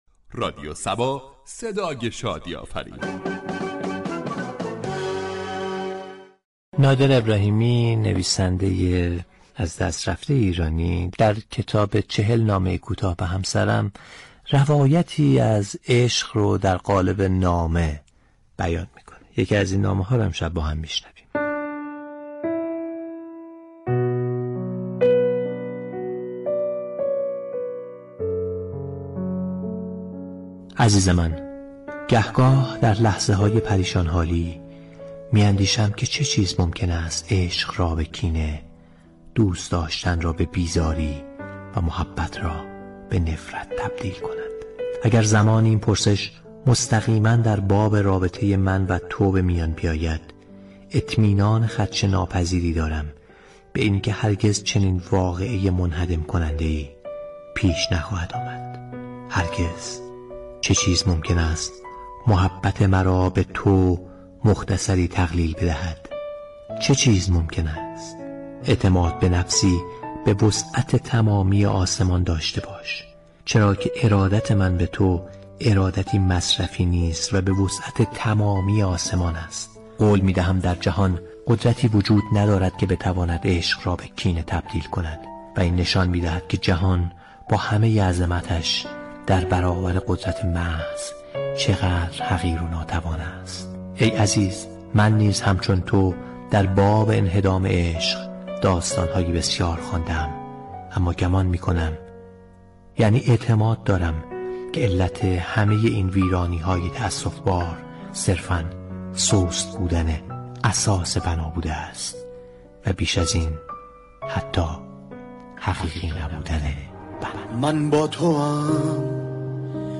در ادامه شنونده بخشی از بخشی از كتاب چهل نامه كوتاه به همسرم به قلم نادر ابراهیمی با صدای منصور ضابطیان از برنامه "بر بوی زلف یار " باشید.